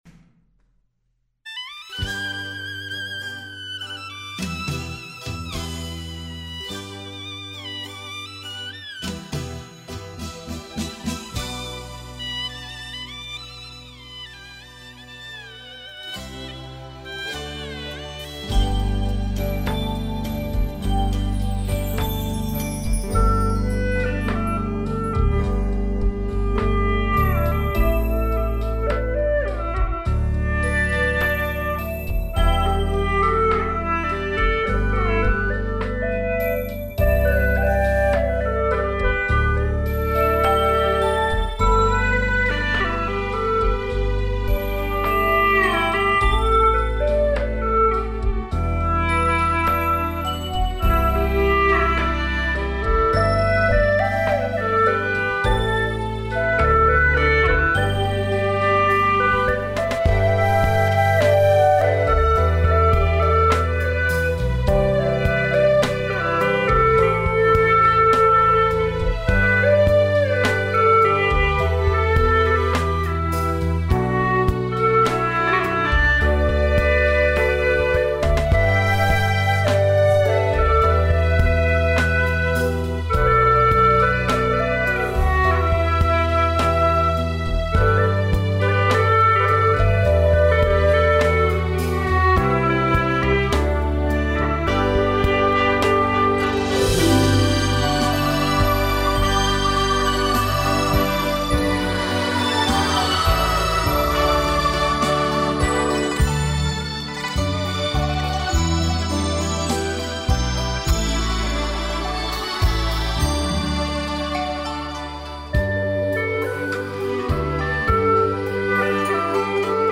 调式 : G 曲类 : 独奏